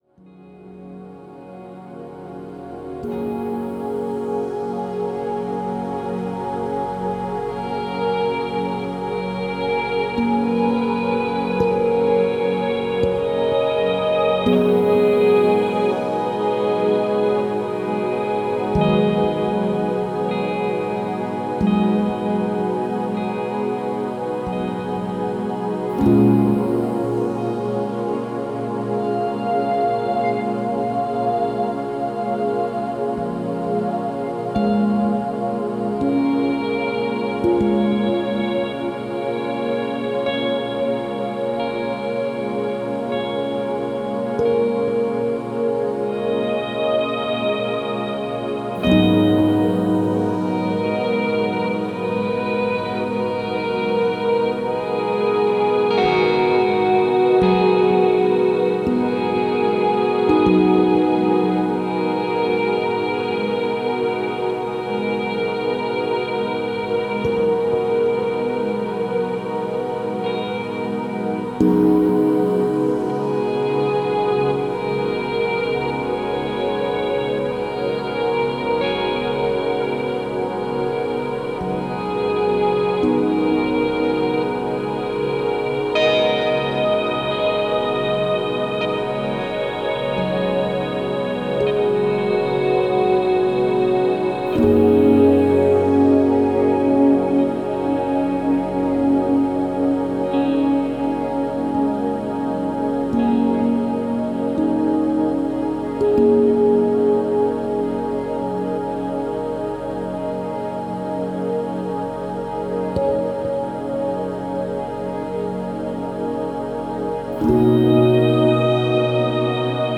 это вдохновляющая композиция в жанре электронной музыки